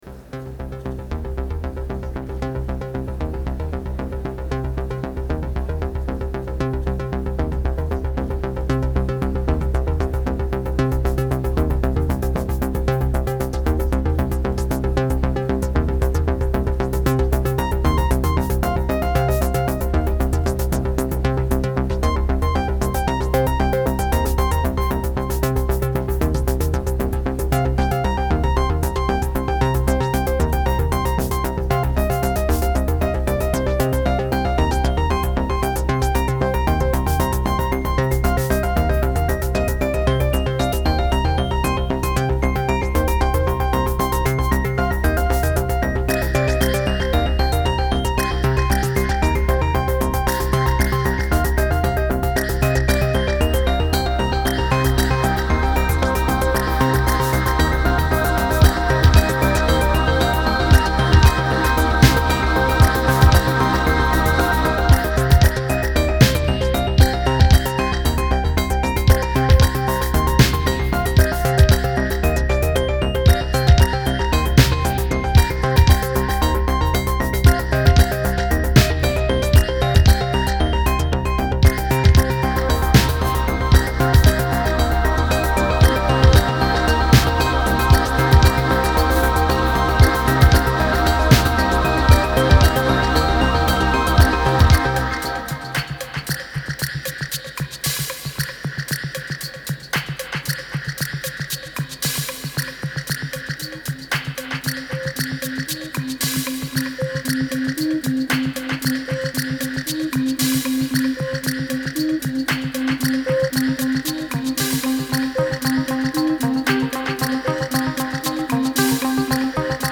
Genre : Techno